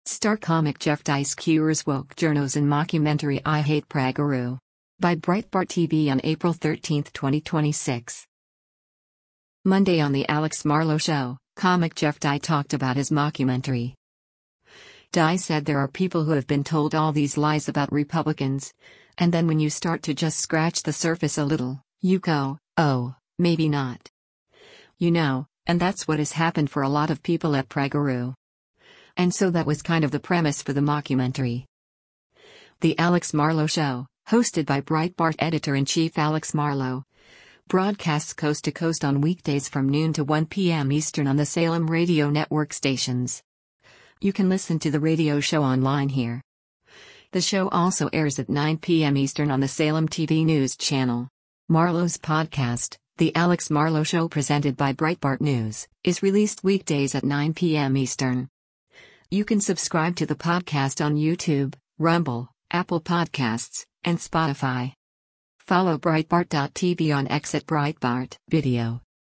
Monday on “The Alex Marlow Show,” comic Jeff Dye talked about his mockumentary.
The Alex Marlow Show, hosted by Breitbart Editor-in-Chief Alex Marlow, broadcasts coast to coast on weekdays from noon to 1 p.m. Eastern on the Salem Radio Network stations.